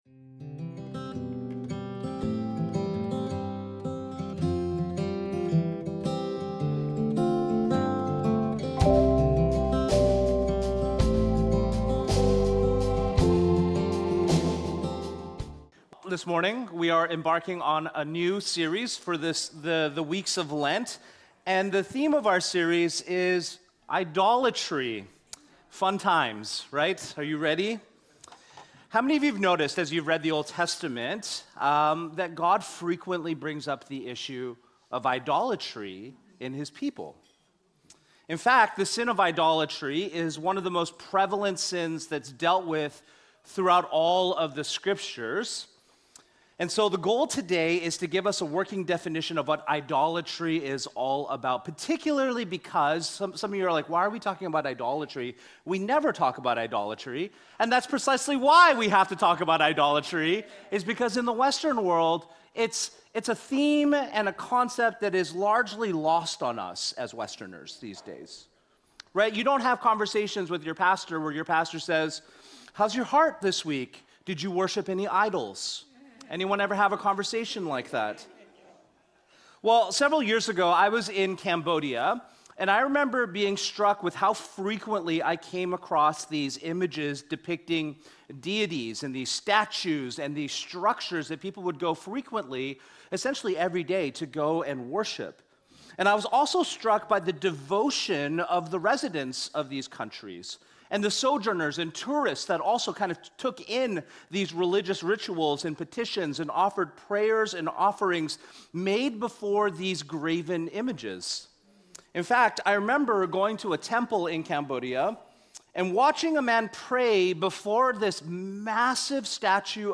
With this message, we embark on a new sermon series entitled: Idol Factory.